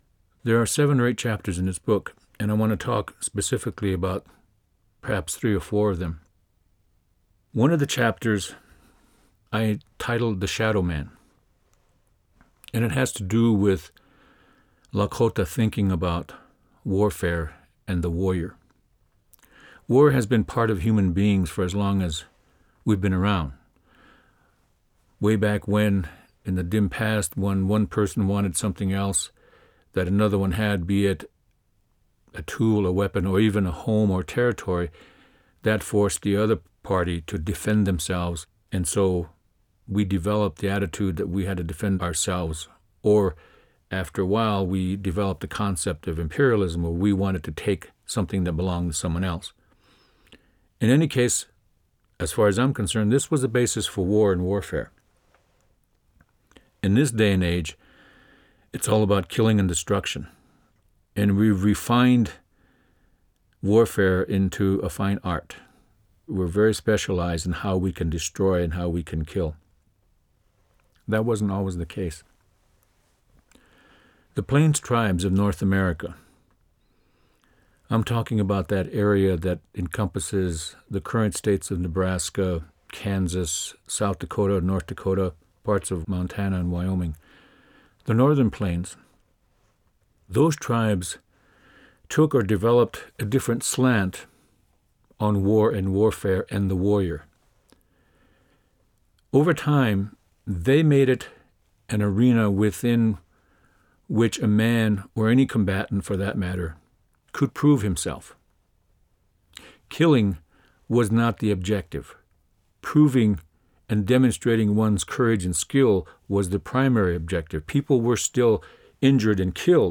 Joseph Marshall* beschreibt das sehr anschaulich in seinem Hörbuch (Track 2 /englisch) und rückt damit Berichte von Europäern über „Die wilden Indianer“ ins rechte Licht.
Ein Indianer erzählt Seit Jahrzehnten schon stelle ich mir diese Frage.